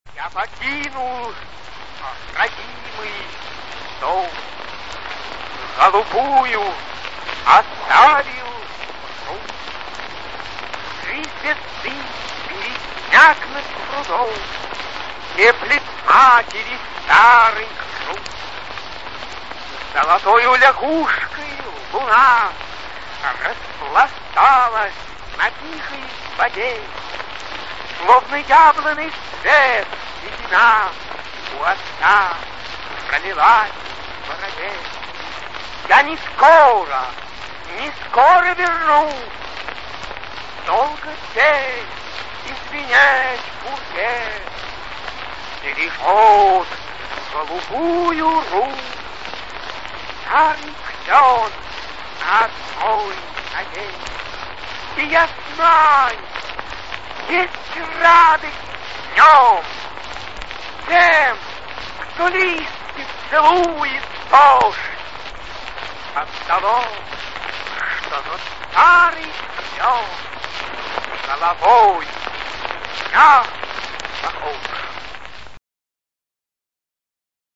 Свои стихи читает автор - Сергей Александрович Есенин
Спасибо за авторскую декламацию С. А. Есенина:)